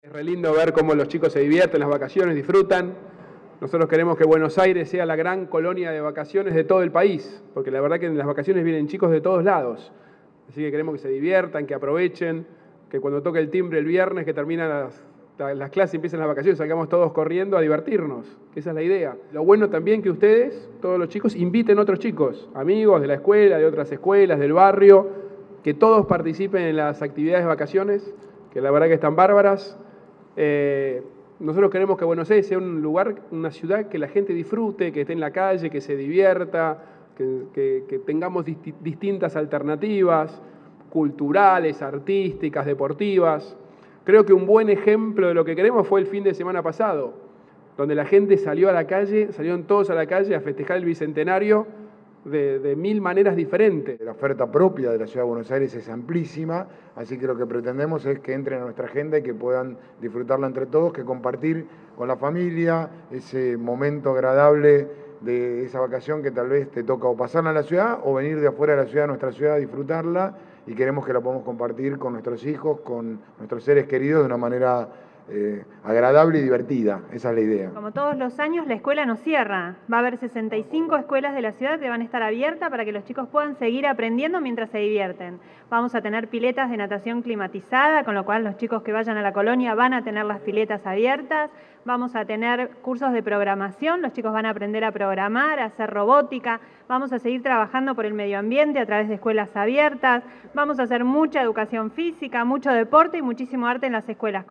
“Es lindo ver cómo los chicos se divierten en las vacaciones y disfrutan”, señaló Rodríguez Larreta al encabezar el anuncio en la Usina del Arte, tras lo cual reafirmó las expectativas de la Ciudad para que “cuando el viernes toque el timbre y terminen las clases, salgamos todos corriendo a divertirnos”.